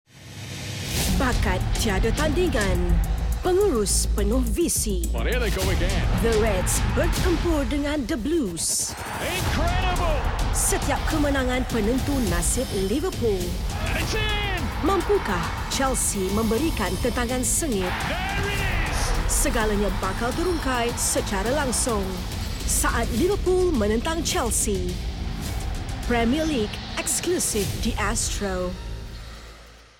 Female
I.V.R. Generic (Malay & English)
Sunquick (Sample) Matured (English)
Scholl (Sample) Deep (Malay)
Friso Gold (Sample) Motherly (English)